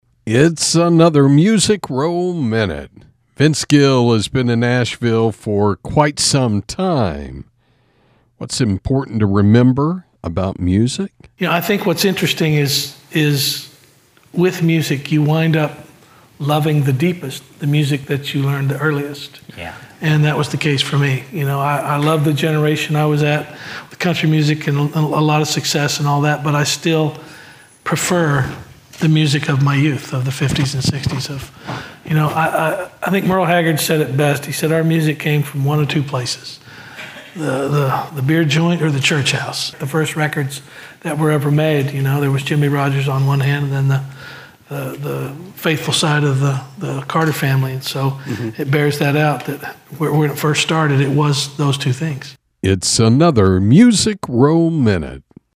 Music Row Minute is a daily radio feature on 106.1FM KFLP